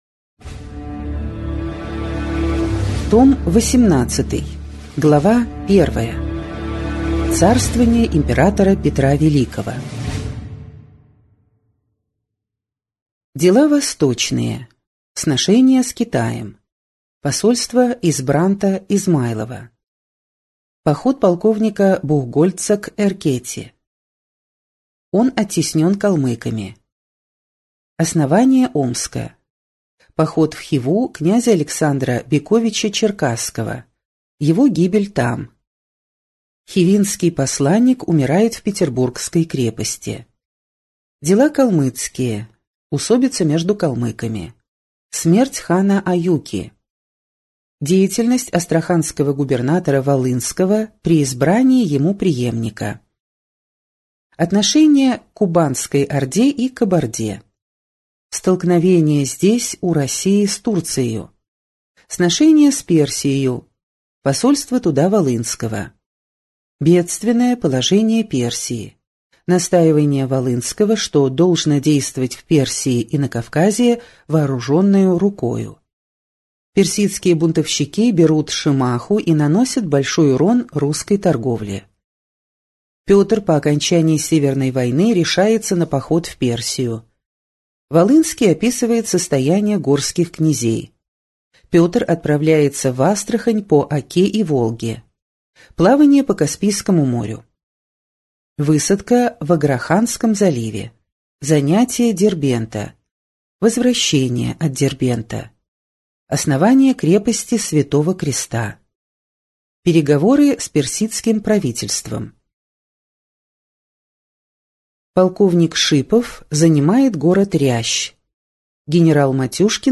Аудиокнига История России с древнейших времен. Том 18.